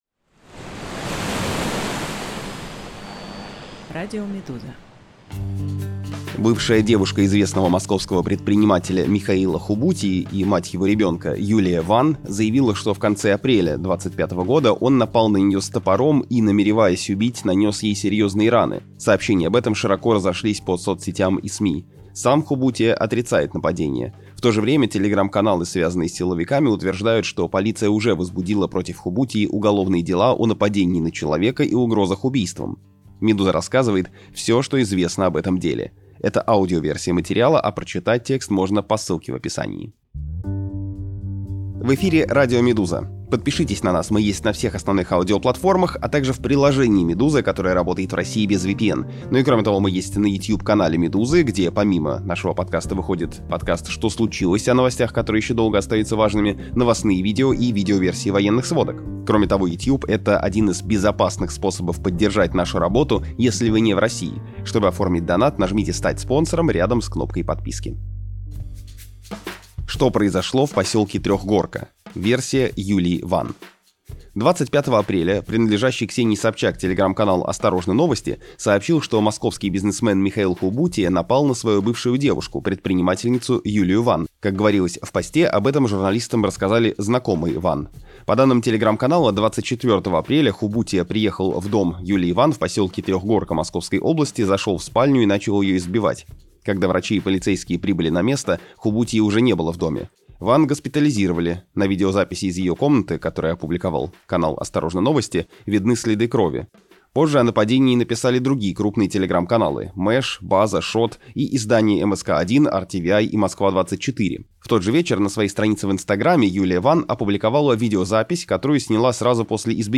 «Медуза» поговорила с некоторыми из них — о переезде в США, сложностях с адаптацией и опасениях перед будущим. Это аудиоверсия материала, а прочитать его можно здесь.